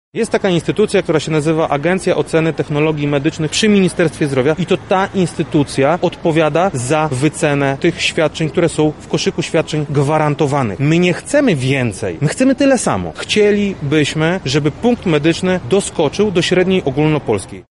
Punkt medyczny w szpitalu Neuropsychiatrycznym w Lublinie jest wyceniany na 12 zł i 20 gr. Podczas gdy średnia ogólnopolska to 15,50– mówi Radny Sejmiku Województwa Lubelskiego z ramienia Koalicji Obywatelskiej Krzysztof Komorski: